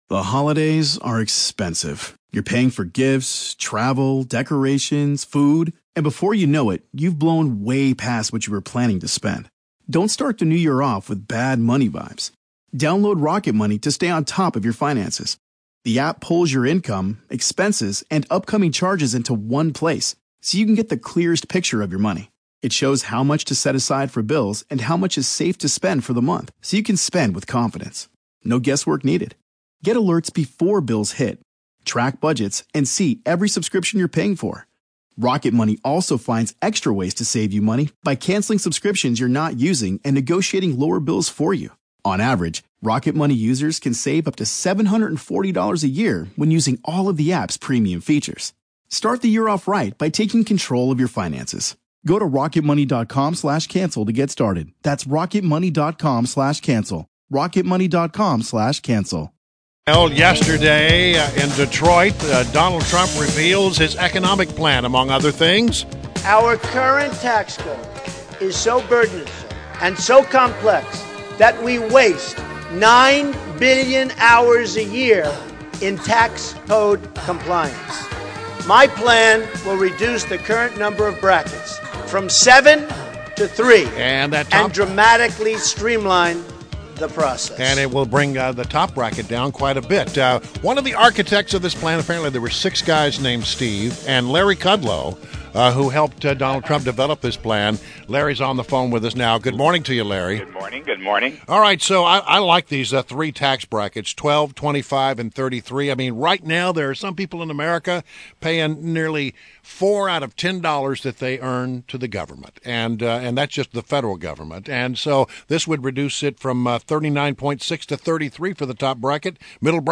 WMAL Interview - LARRY KUDLOW - 08.09.16